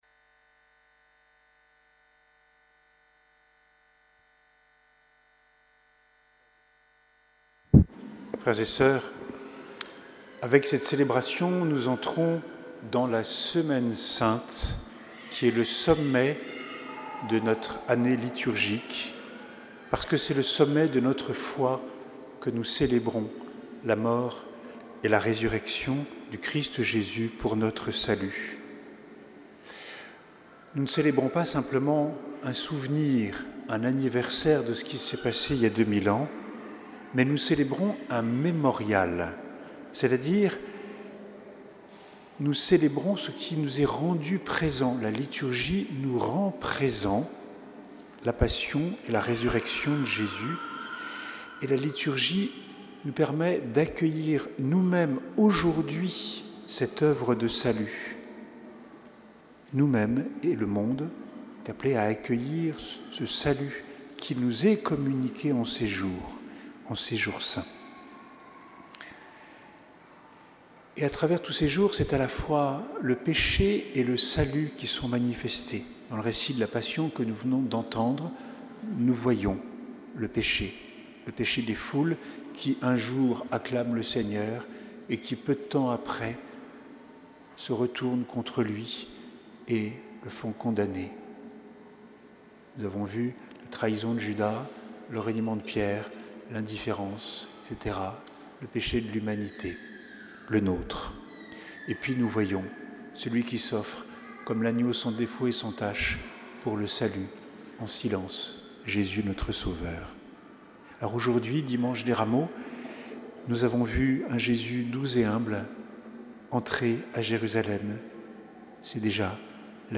Homélie du dimanche des Rameaux et de la Passion